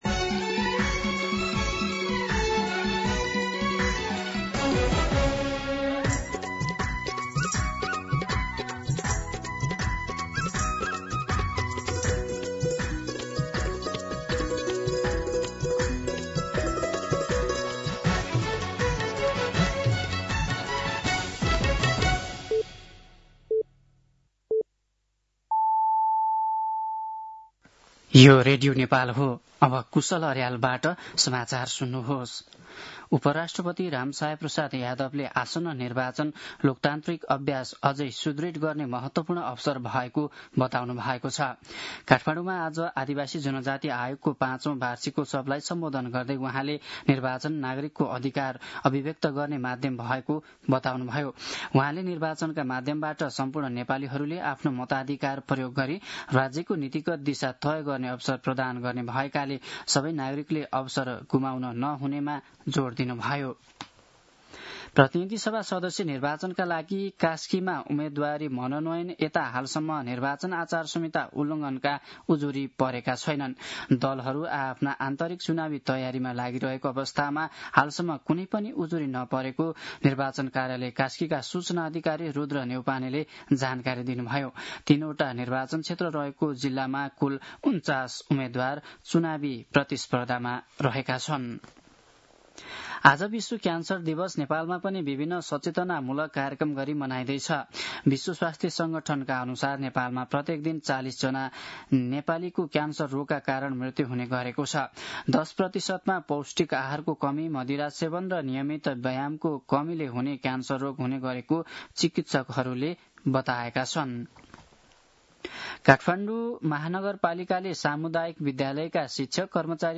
दिउँसो ४ बजेको नेपाली समाचार : २१ माघ , २०८२
4-pm-Nepali-News.mp3